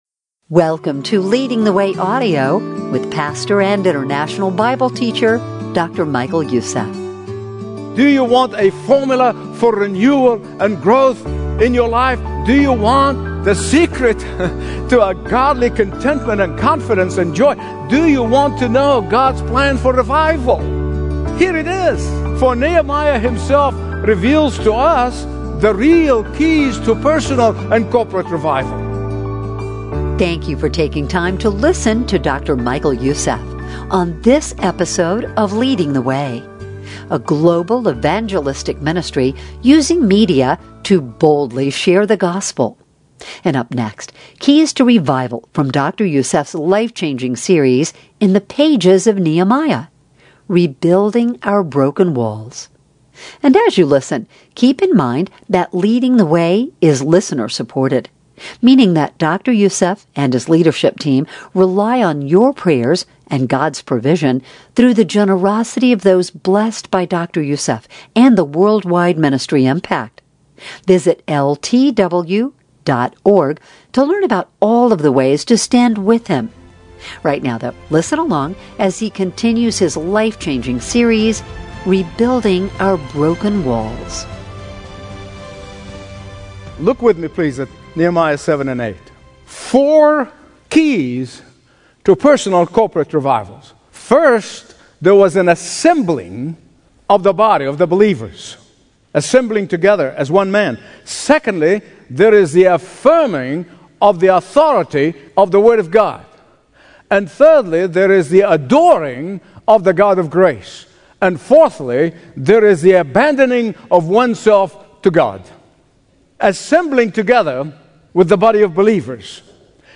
Daily Bible Teachings
Stream Expository Bible Teaching & Understand the Bible Like Never Before.